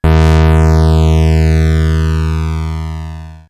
Hum38.wav